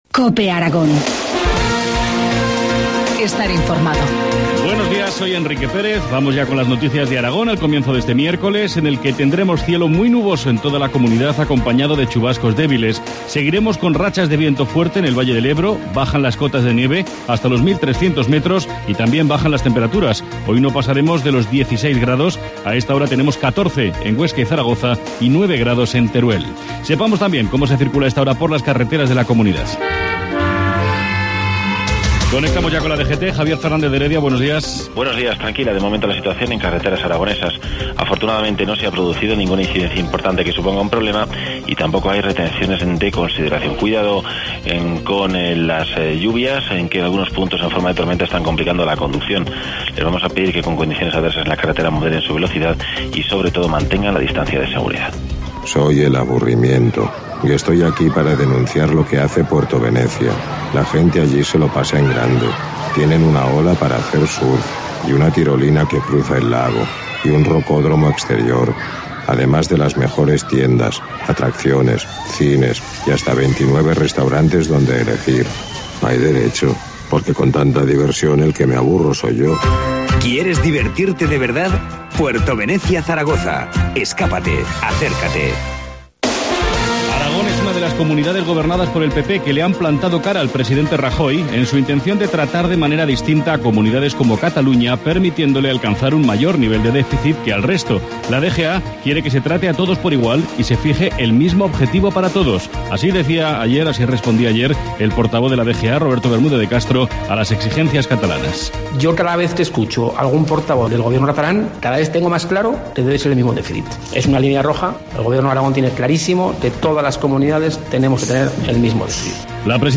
Informativo matinal, miércoles 15 de mayo, 7.25 horas